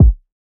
Metro Kick 6 .wav